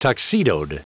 Prononciation du mot tuxedoed en anglais (fichier audio)
Prononciation du mot : tuxedoed